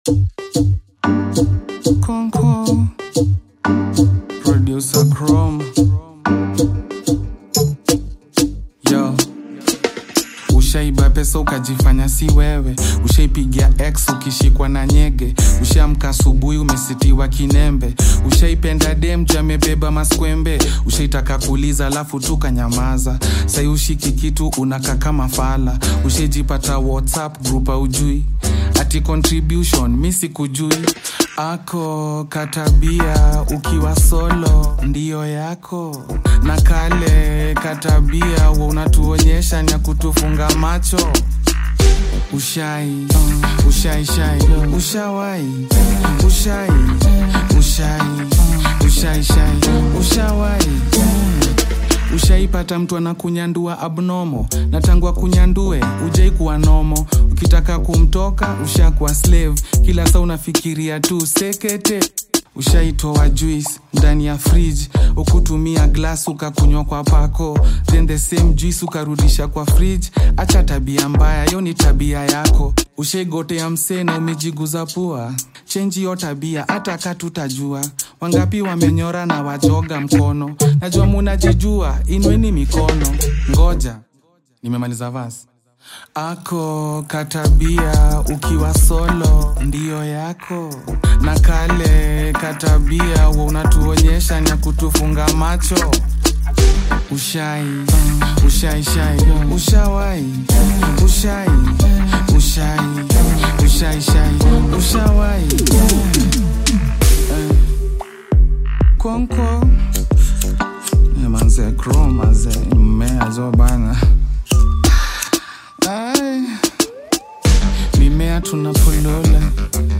Kenyan hip hop